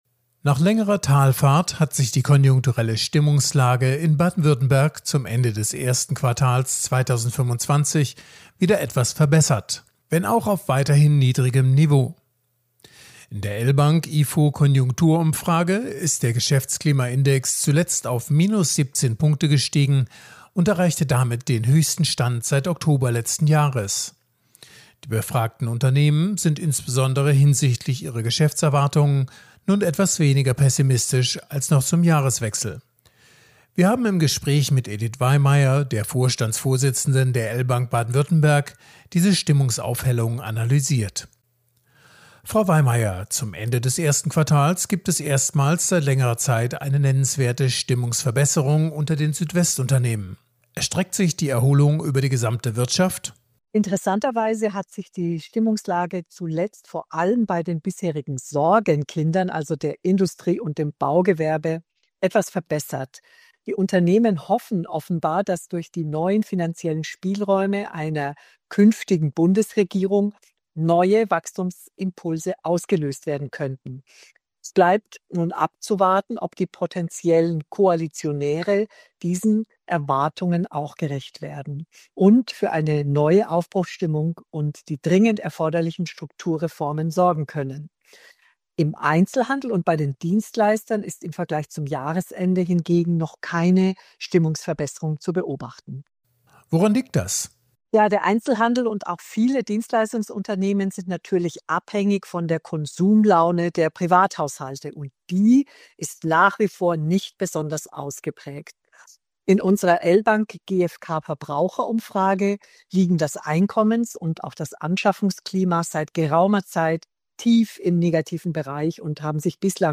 Sendefähige Interviews, Statements und O-Töne zu aktuellen Themen
Konjunktur-Interview